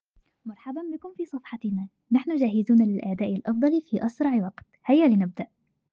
Voice over فويس اوفر بالمصرية والخليجيه